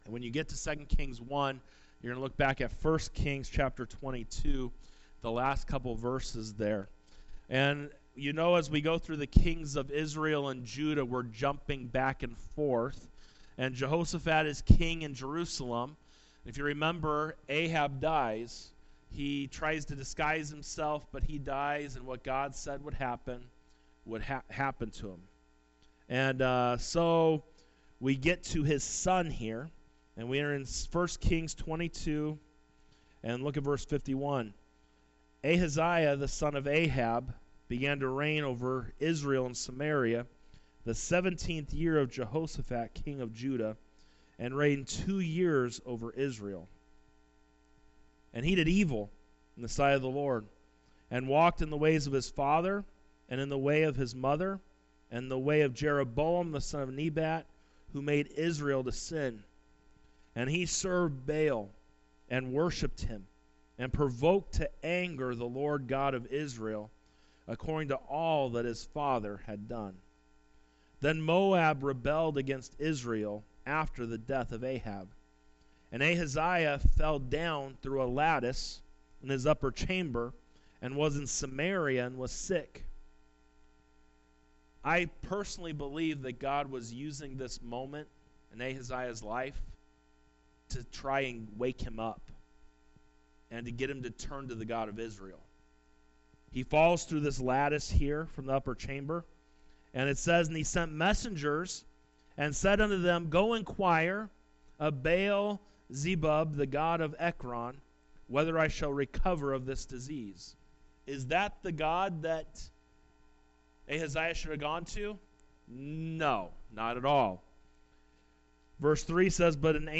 Sermons | Victory Baptist Church